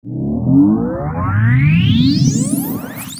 shipAscend.wav